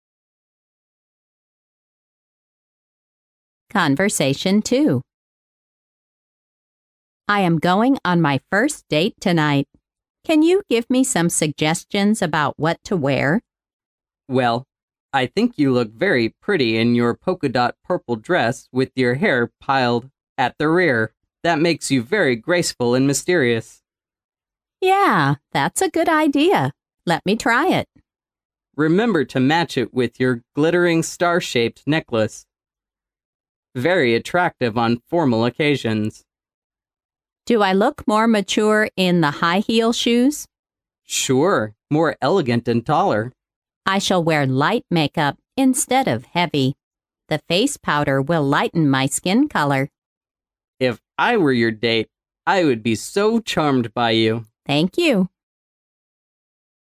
潮流英语情景对话张口就来Unit5：第一次约会mp3